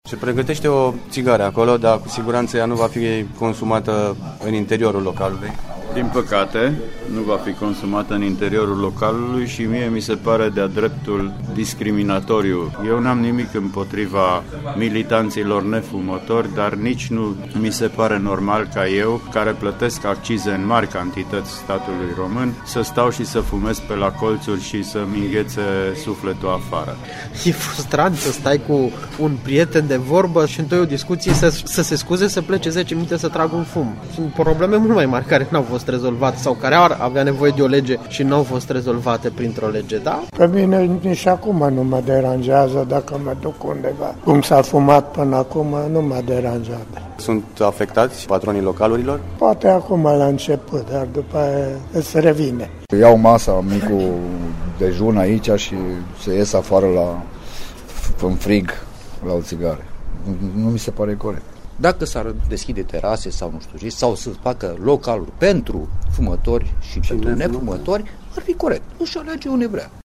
În această dimineaţă, am stat de vorbă cu mai mulţi clienţi într-un bar în care se consuma cafea, ceai sau apă, dar fără ţigară. Majoritatea fumătorilor se simt discriminaţi sau frustraţi, fiind nevoiţi să părăsească incinta localului pentru a putea fuma.
vox-fumatori-pt-site.mp3